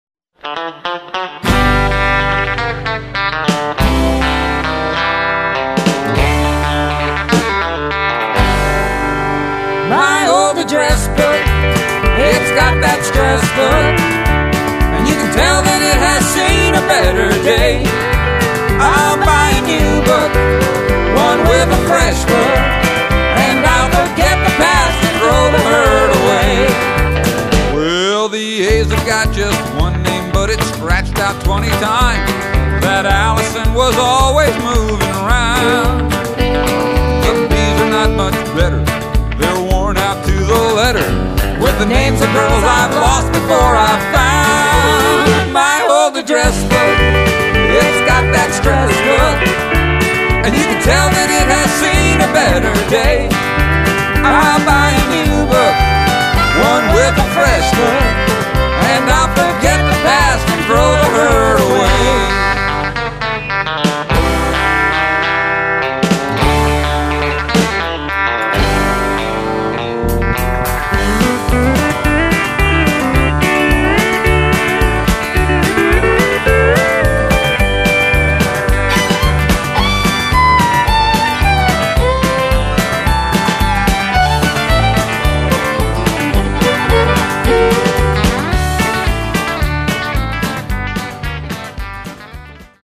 modern Country/Rockabilly
They have a great beat
Upright Bass
Telecaster, topped off with Fiddle and Drums.